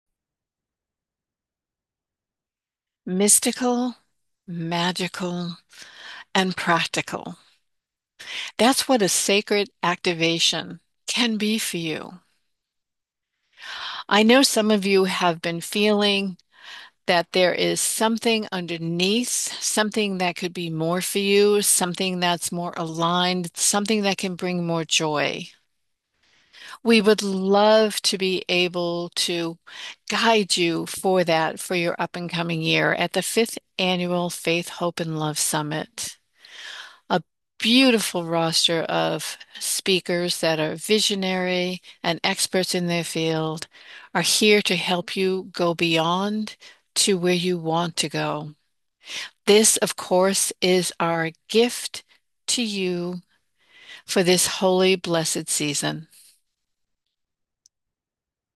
🎧 I even recorded a short personal audio message—from my heart to yours.